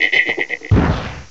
cry_not_salandit.aif